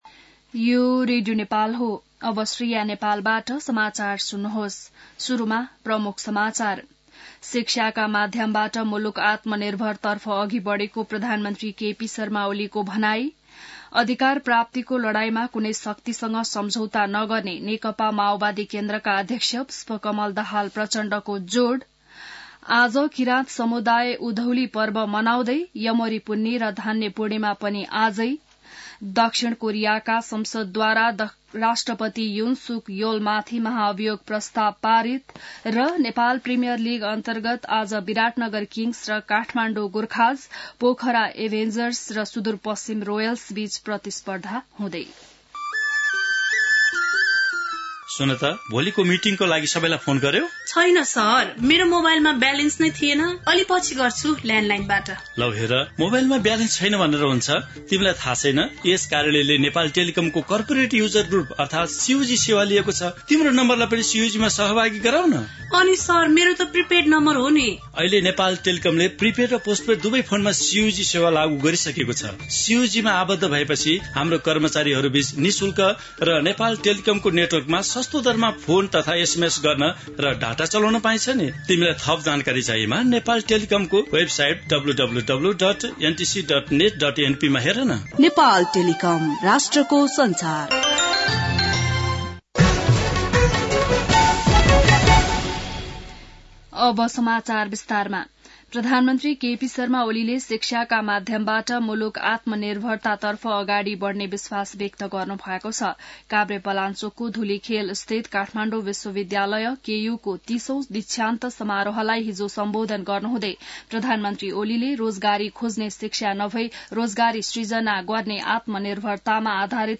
बिहान ७ बजेको नेपाली समाचार : १ पुष , २०८१